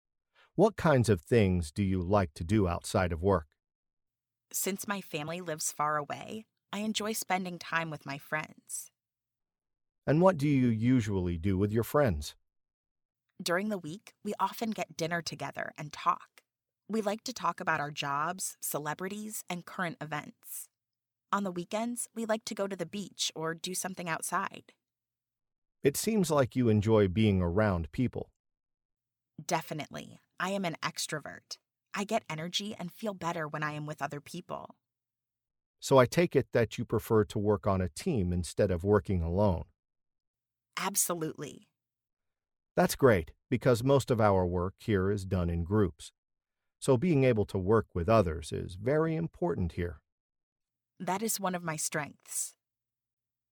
Learn different ways to answer the interview question 'What kinds of things do you like to do outside of work?', listen to an example conversation, and study example sentences